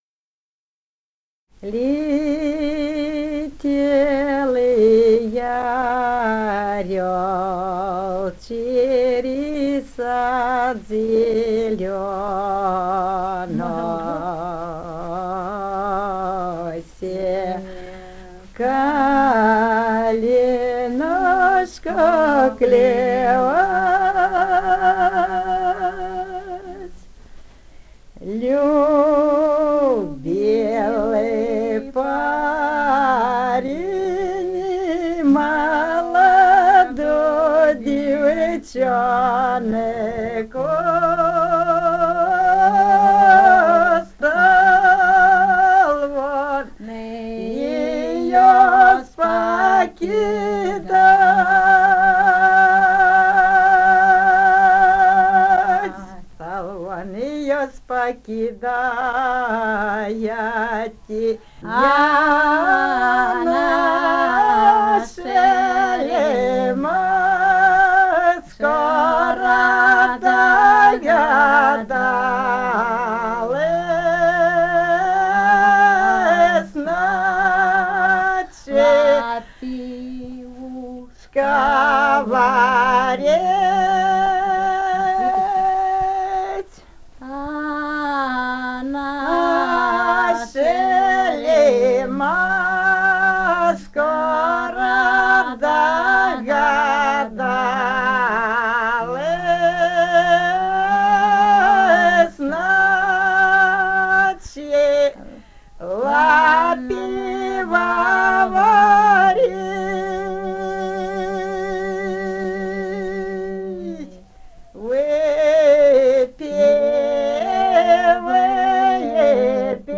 Этномузыкологические исследования и полевые материалы
Румыния, с. Переправа, 1967 г. И0973-01